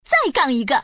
Index of /guizhou_ceshi/update/1601/res/sfx/woman/